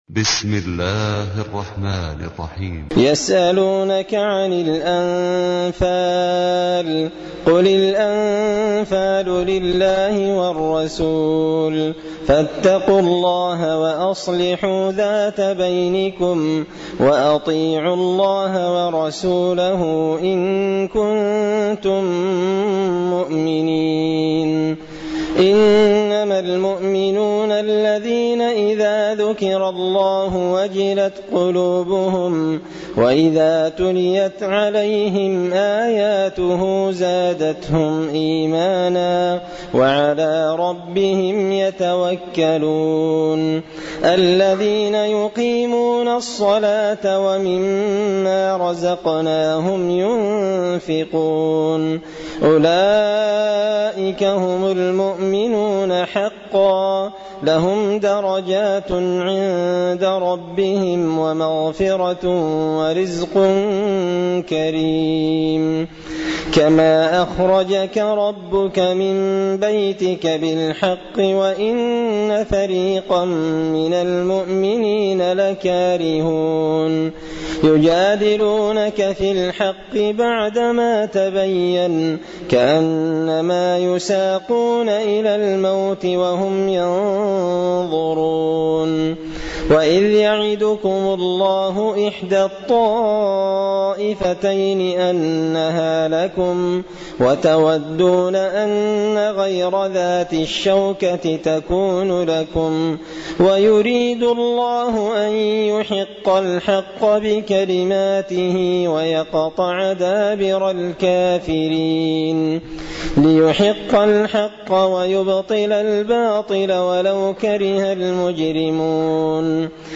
تلاوة سورة الأنفال
دار الحديث بمسجد الفرقان ـ قشن ـ المهرة ـ اليمن